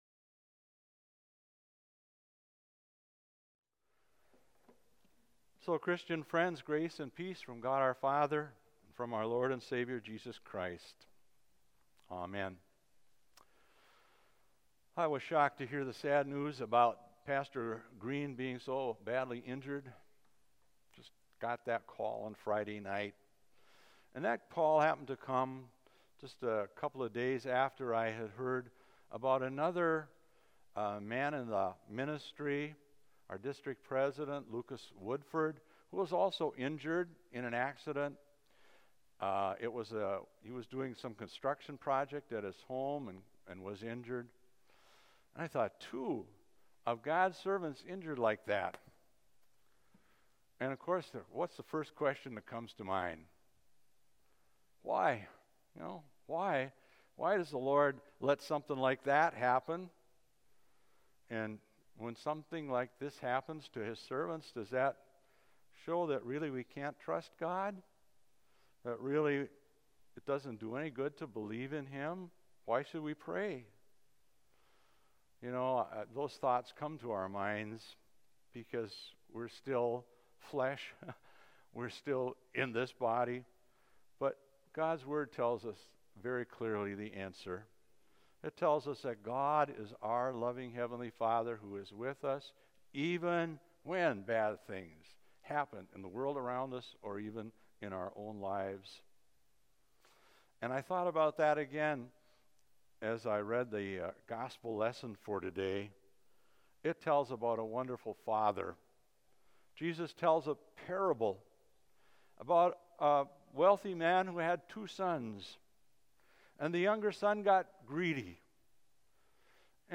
Sermon on the Mount – Luke 15